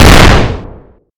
Small Explosion
bang bomb boom distortion dynamite explosion grenade loud sound effect free sound royalty free Memes